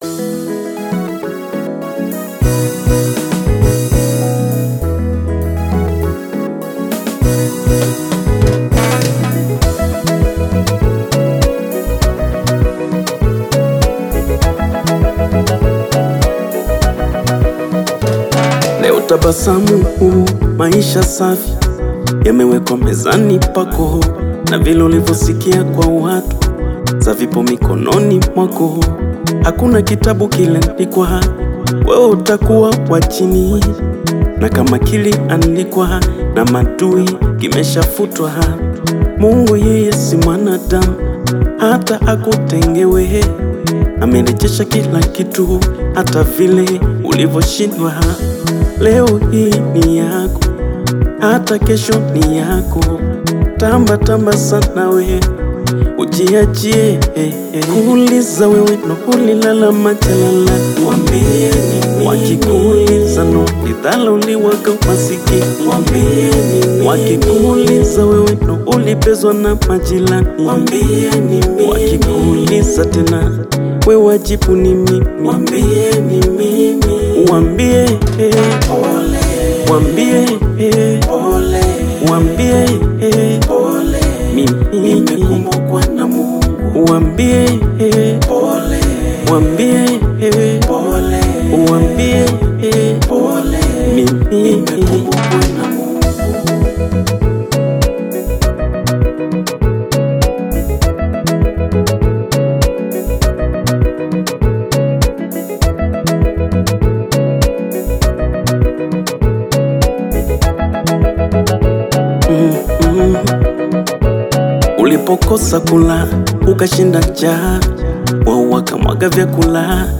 soulful sounds
With its atmospheric production and hypnotic melodies